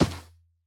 Minecraft Version Minecraft Version snapshot Latest Release | Latest Snapshot snapshot / assets / minecraft / sounds / mob / camel / step_sand5.ogg Compare With Compare With Latest Release | Latest Snapshot
step_sand5.ogg